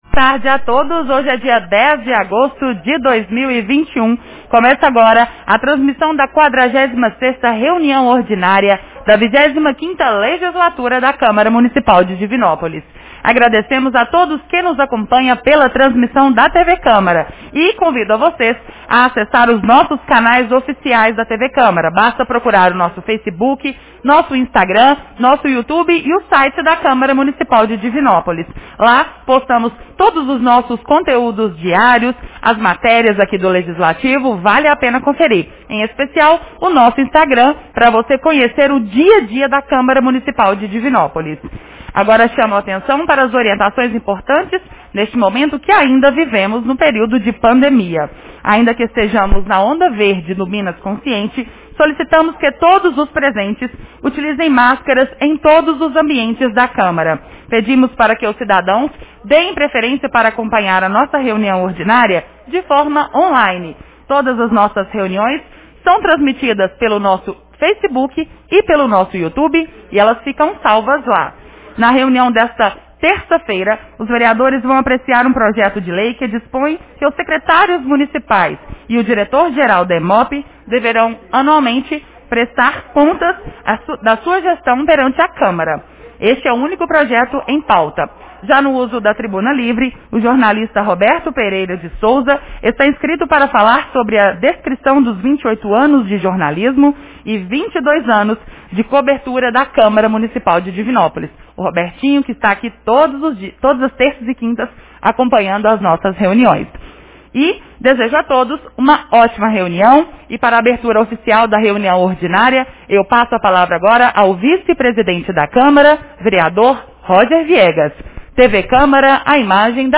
Reunião Ordinária 46 de 10 de agosto 2021